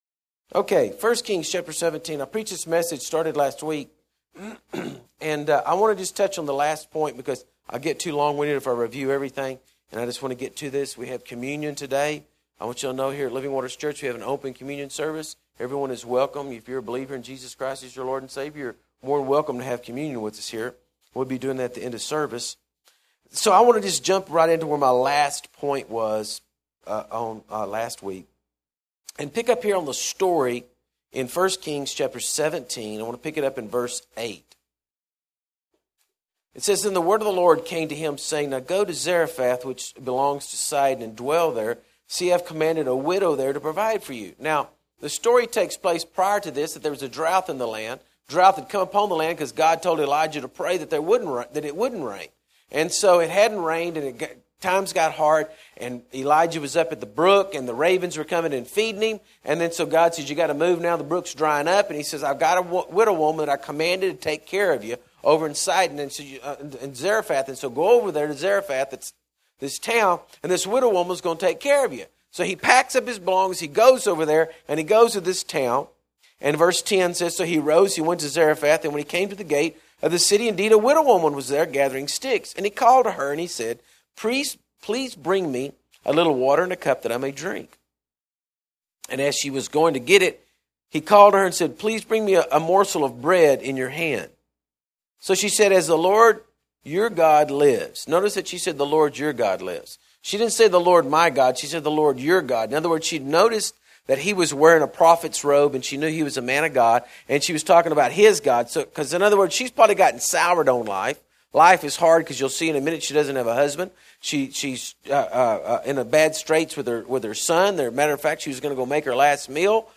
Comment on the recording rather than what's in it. Sunday Services May 10 Downloads To download right click on the message and select (save target as) or (save link as) 5-30-10 Compromise 5-16-10 Radical Part II 5-9-10 Radical Living 5-2-10 Producing Faith 2